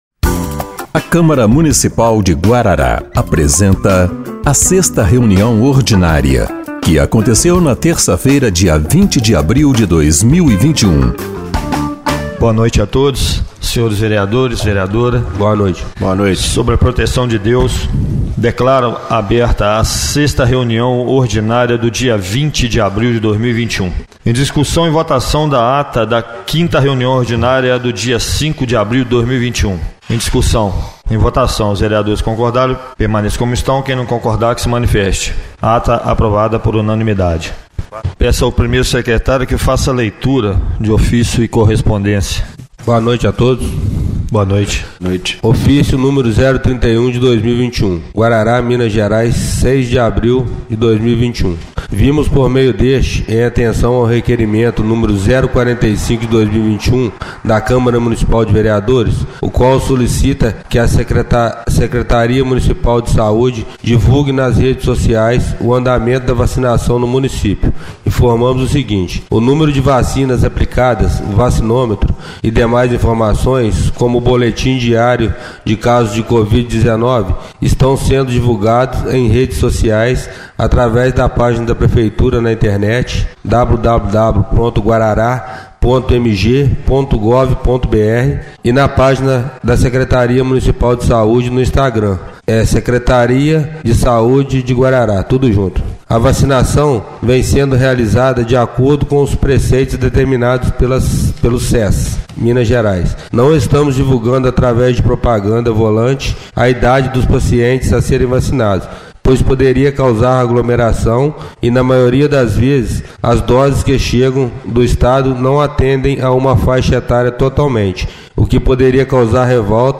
6ª Reunião Ordinária de 20/04/2021